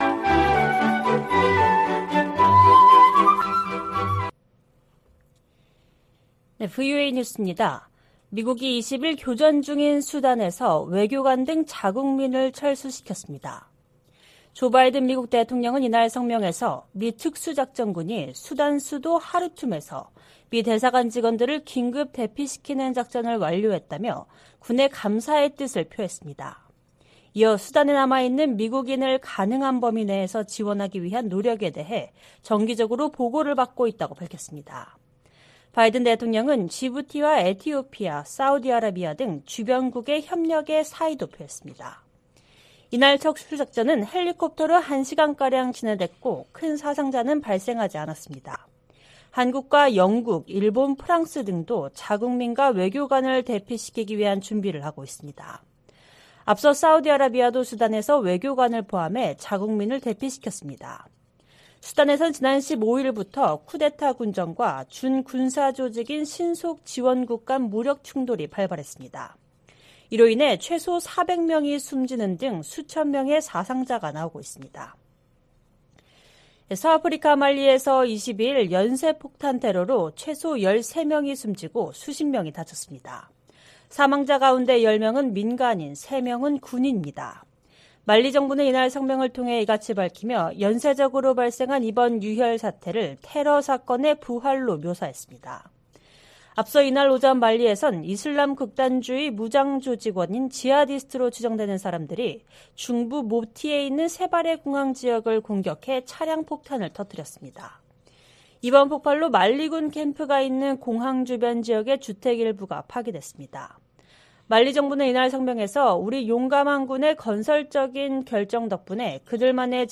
VOA 한국어 방송의 일요일 오후 프로그램 3부입니다.